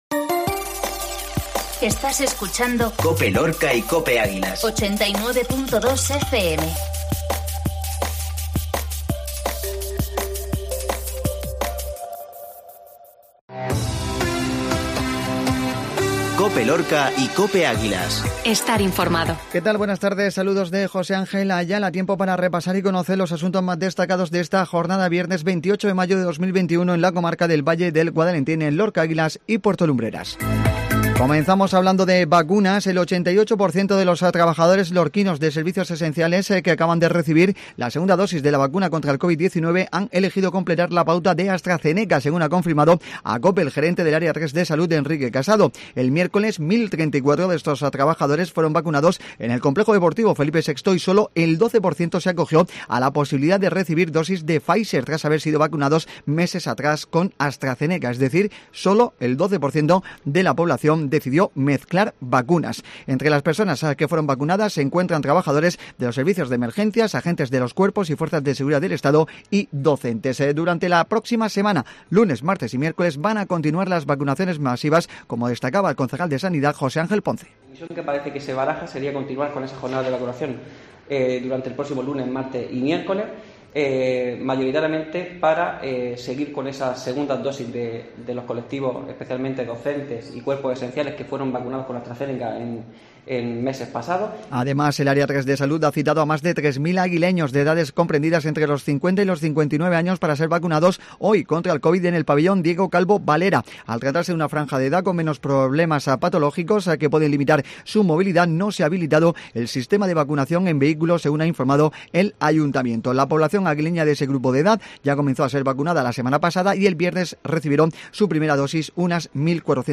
INFORMATIVO MEDIODÍA VIERNES